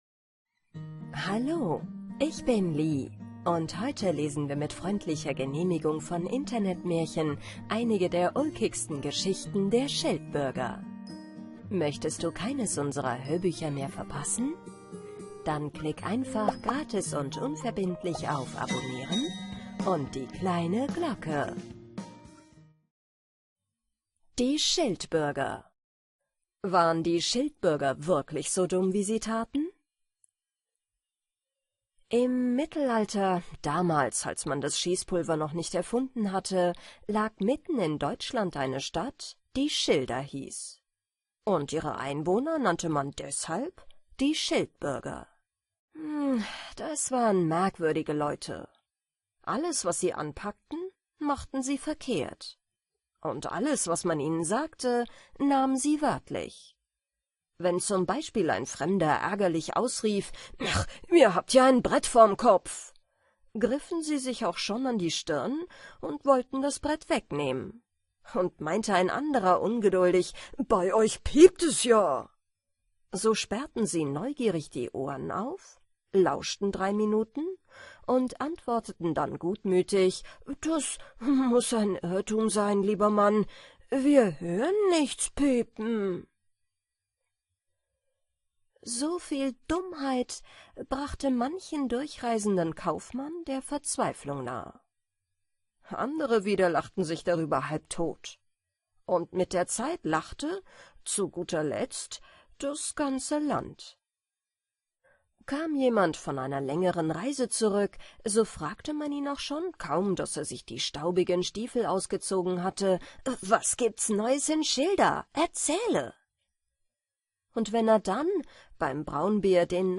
Die Schildbürger: Das lustigste Hörbuch, das Sie garantiert lachend einschlafen lässt